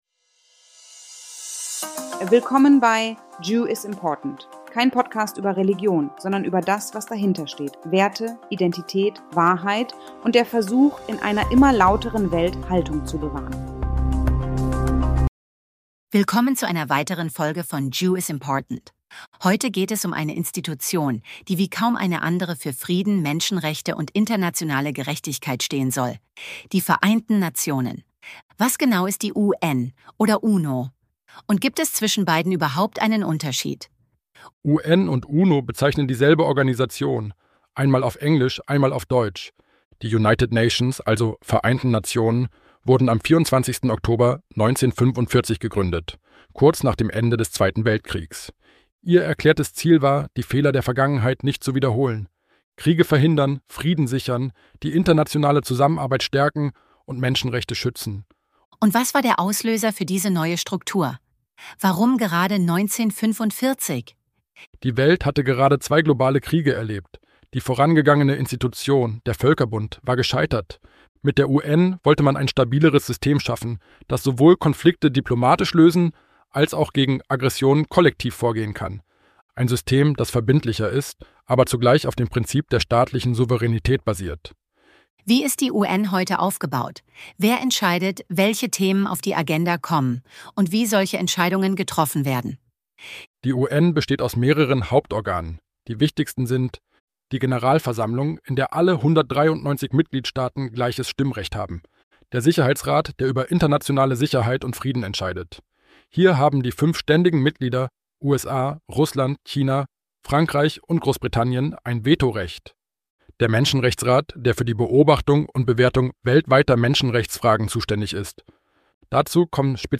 © 2025 AI-generated content.